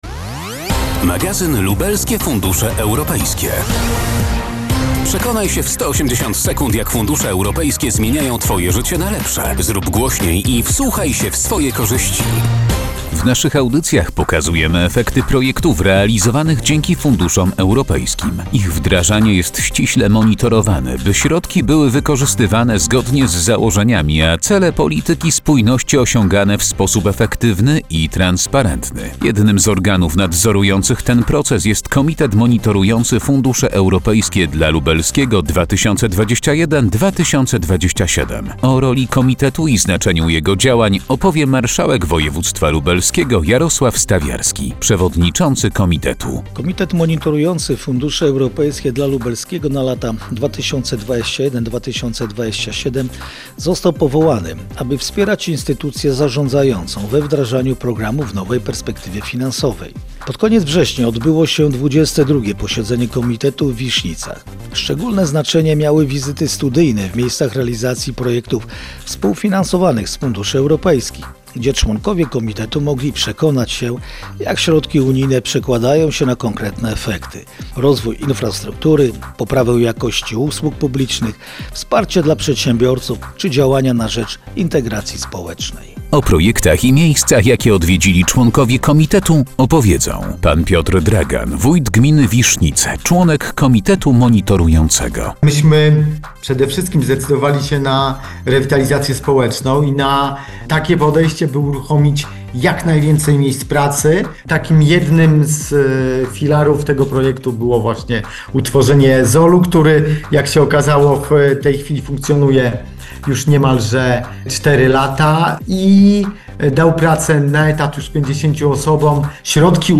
Audycja radiowa 180 sek. - nabory